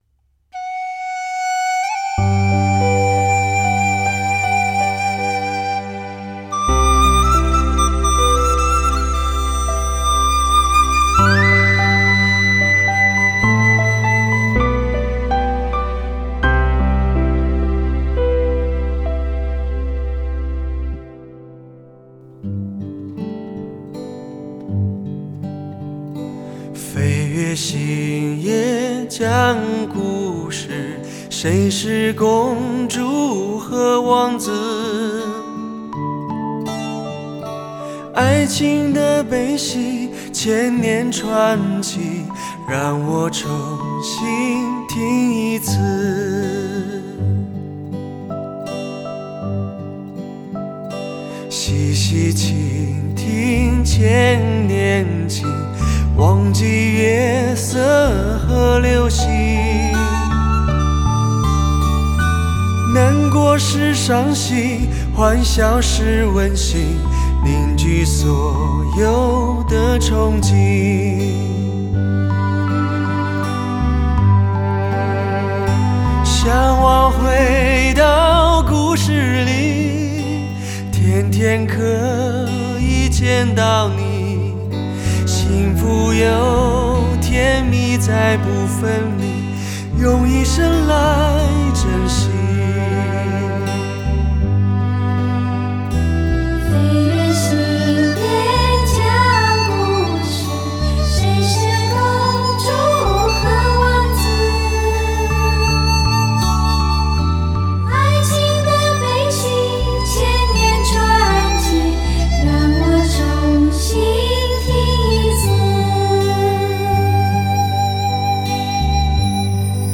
或爵士或民歌或流行，有独唱、合唱、对唱，气氛一流